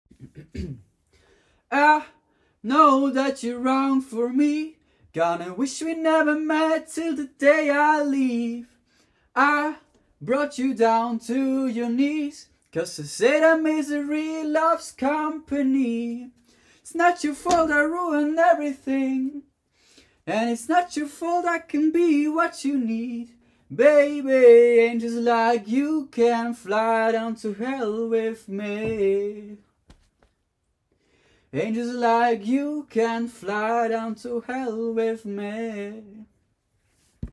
(Stimmdemo vorhanden)